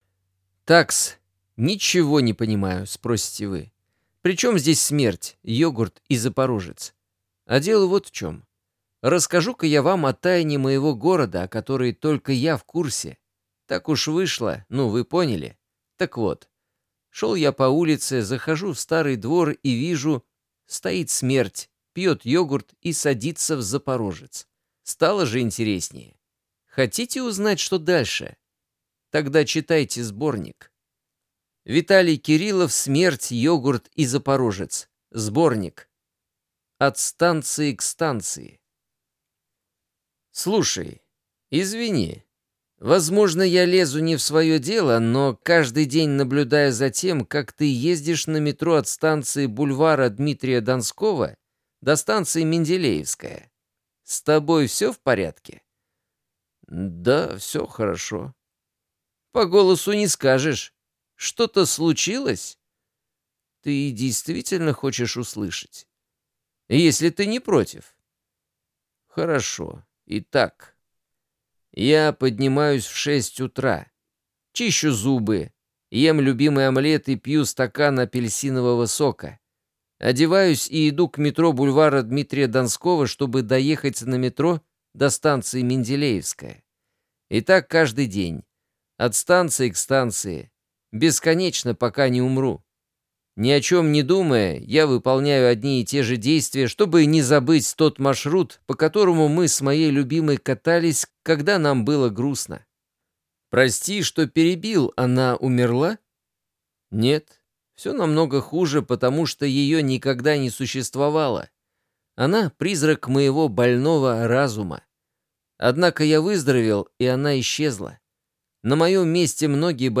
Аудиокнига Смерть, йогурт и запорожец! Сборник | Библиотека аудиокниг